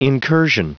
Prononciation du mot incursion en anglais (fichier audio)
Prononciation du mot : incursion